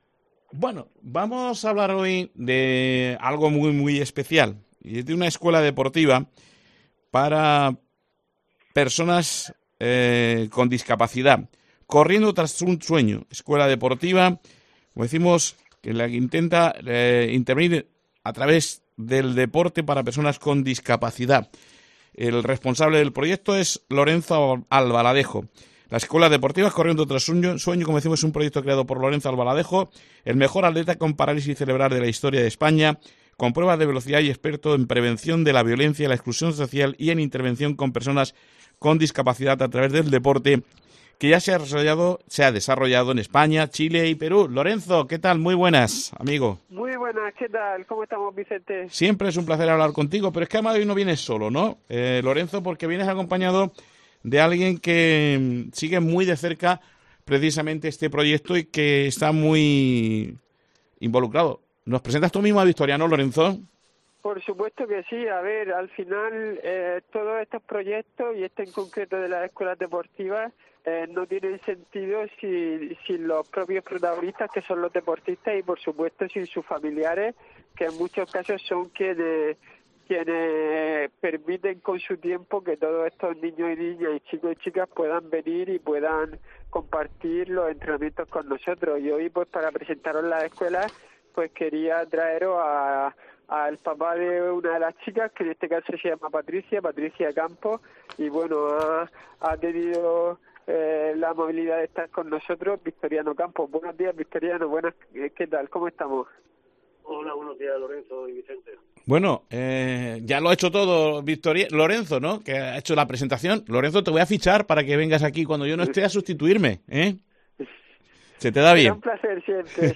nos han explicado en COPE Murcia sus experiencias.